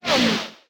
fastCarFar.ogg